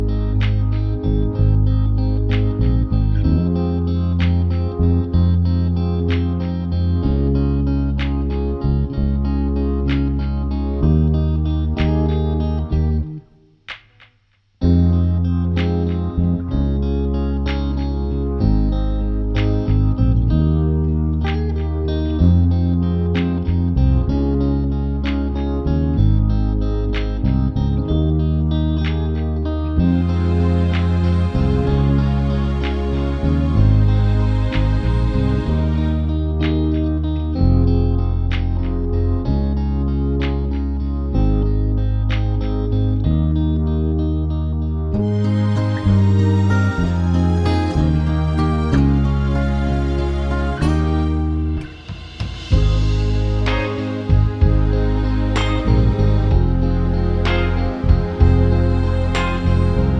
No Lyric Backing Vocals Pop (2020s) 3:55 Buy £1.50